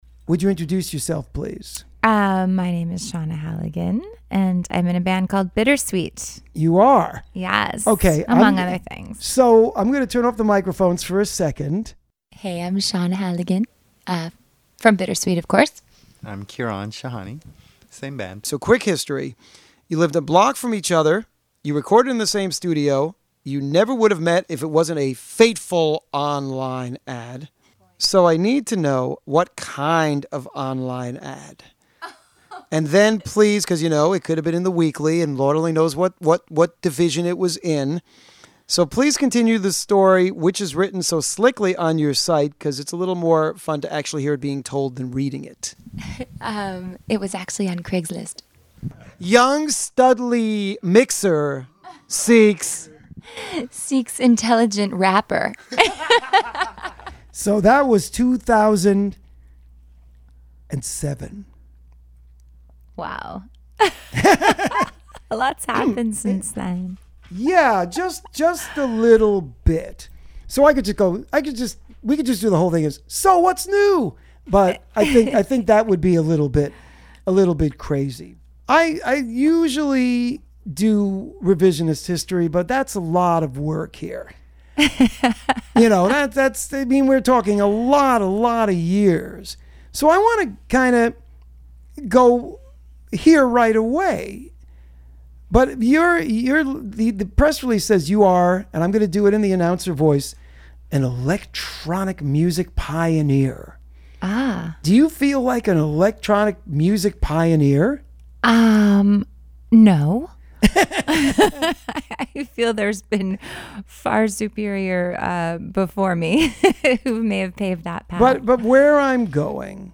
This Week's Interview (04/20/2025): Bitter:Sweet LISTEN TO THE INTERVIEW